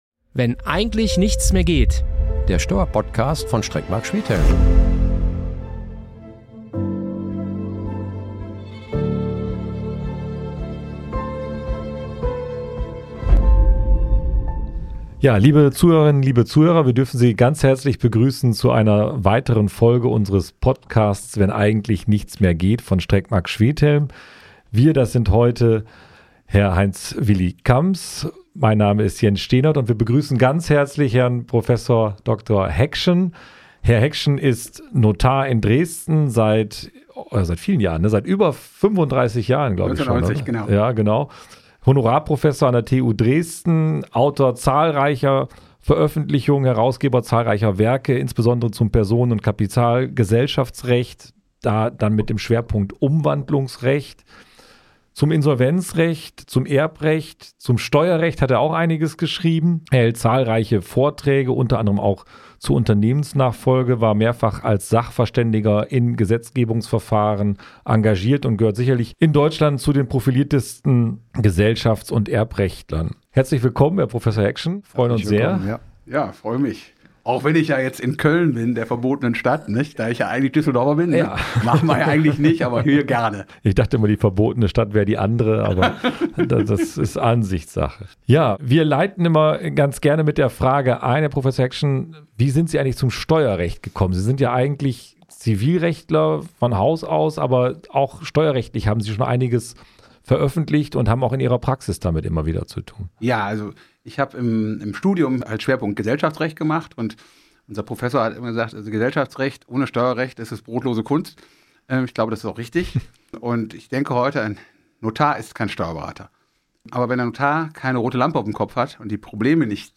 Ein fachlich fundierter, aber bewusst leicht gehaltener Austausch, für all diejenigen, die Unternehmer:innen bei diesem sensiblen Prozess begleiten.